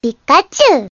Звуки Пикачу